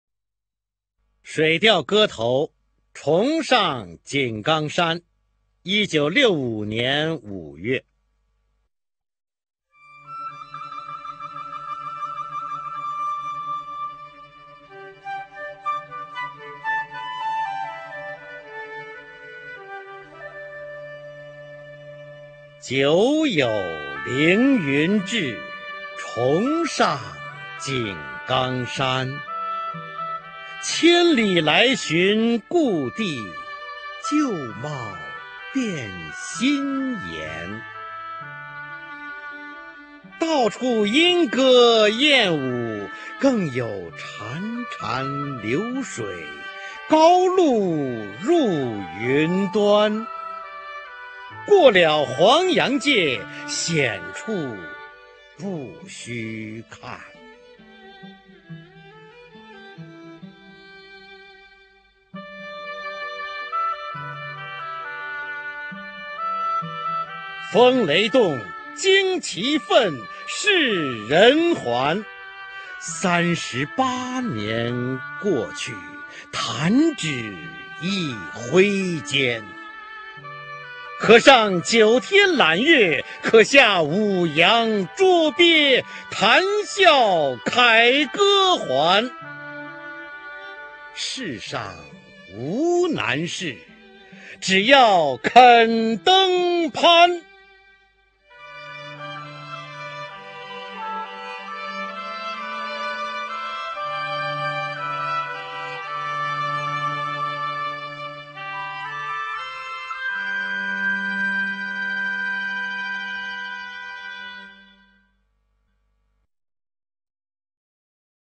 [16/12/2013]方明配乐诗朗诵：水调歌头·重上井冈山
朗诵：方明